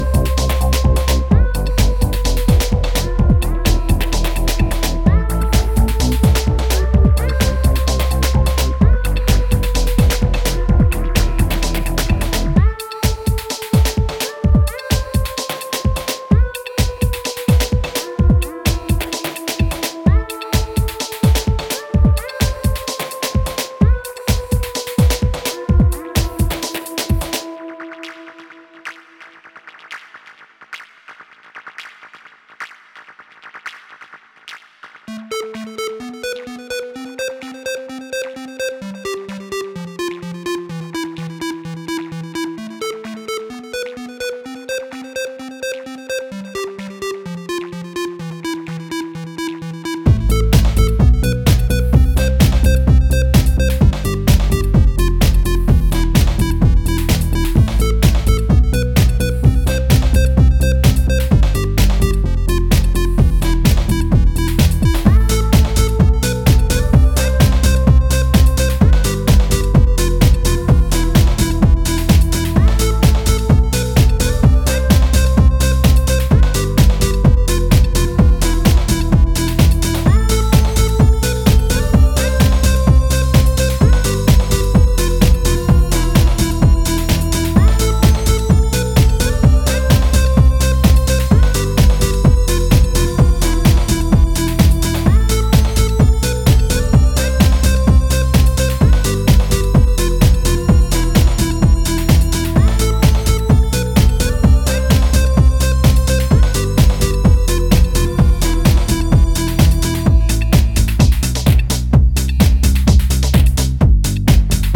stepping up with four cuts of moody spirited machine sounds.
Heads-down, locked in music full of precision and class.